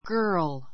girl 小 A1 ɡə́ː r l ガ ～ る 名詞 複 girls ɡə́ː r lz ガ ～ るズ ❶ 女の子 , 少女 ; （若い） 女性 ✓ POINT 女の赤ちゃんから, 17, 18歳 さい ぐらいの若い女性まで幅 はば 広く使う.